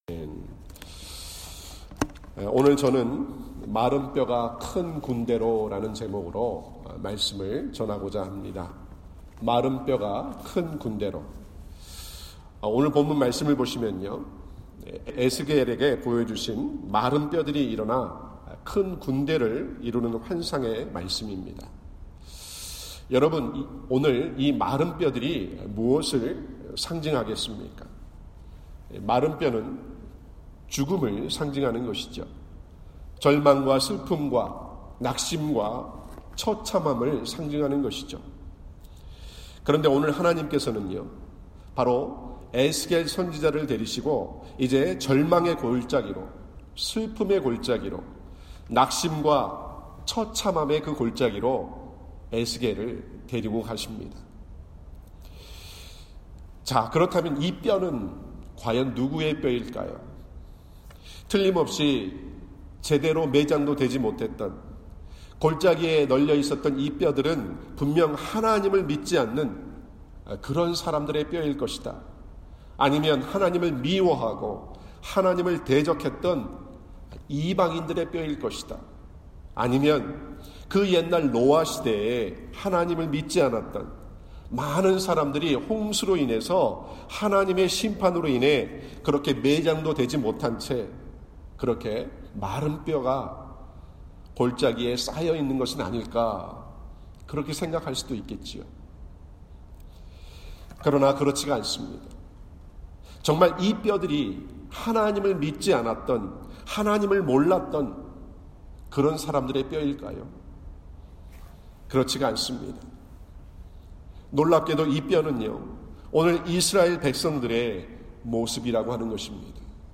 4/20-25 새벽 예배 온라인 설교
영상과 음향이 고르지 못해 녹음 파일을 올립니다.